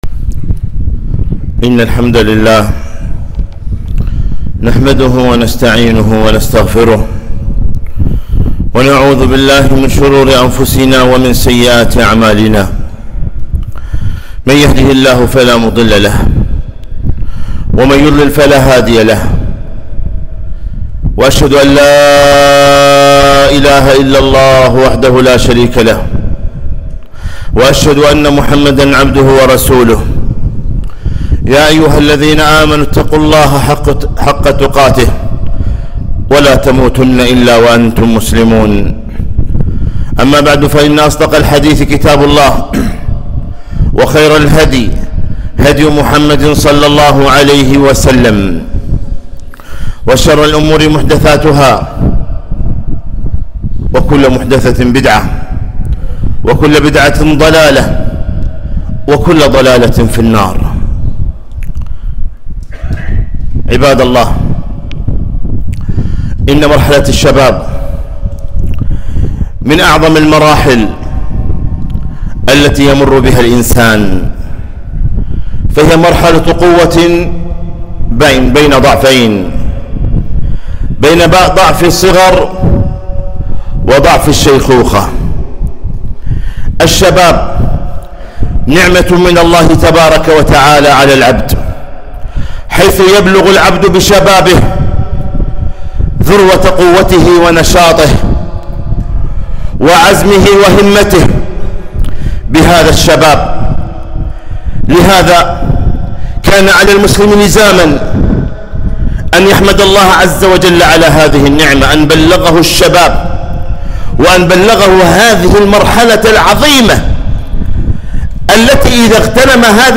خطبة - رسالة إلى الشباب